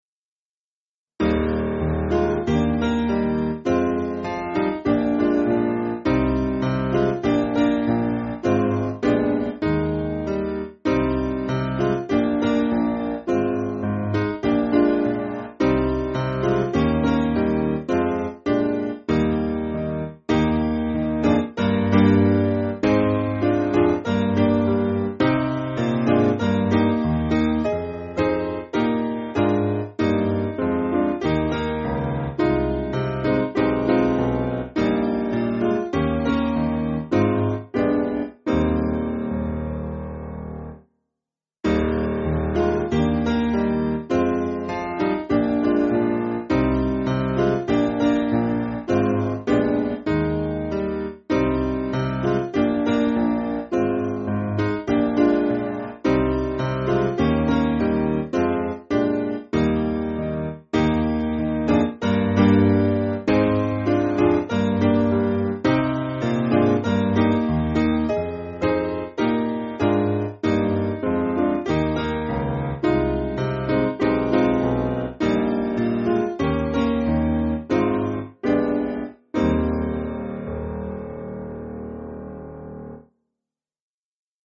Simple Piano
(CM)   2/Eb